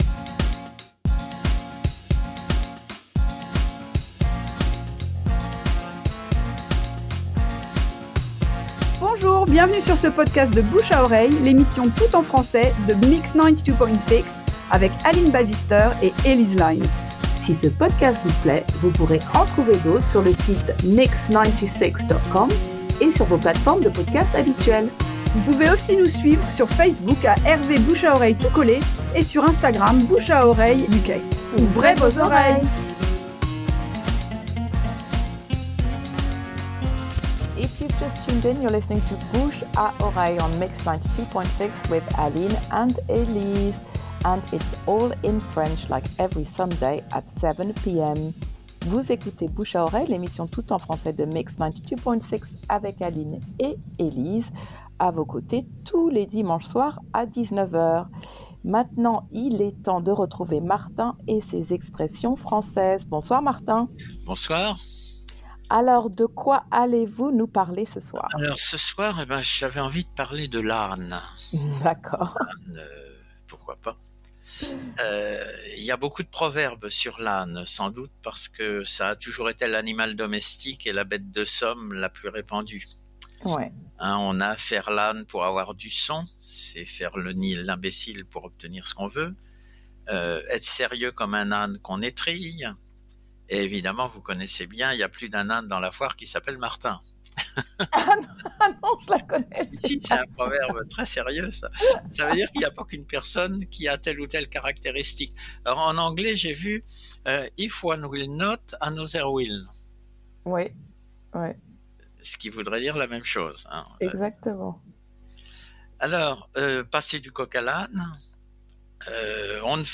Extrait d’émission diffusée en mai 2023.